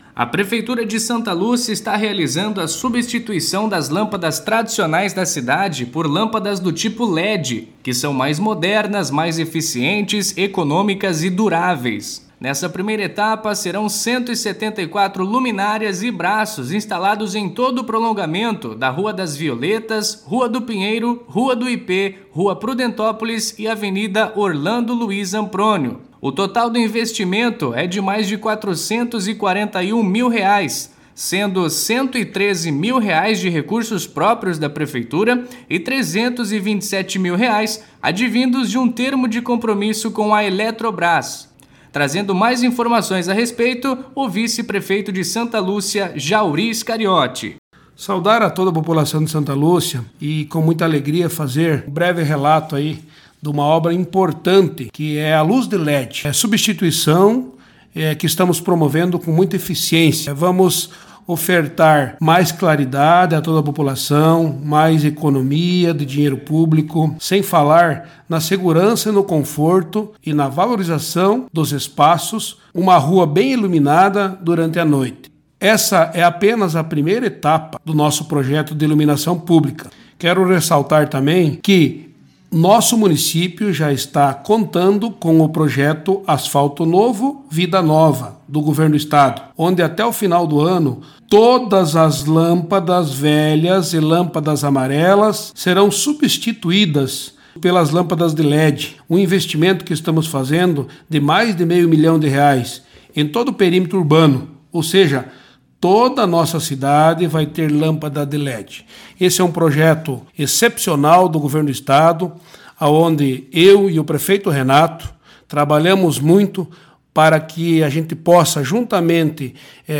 Mat�ria em �udio, Substitui��o de l�mpadas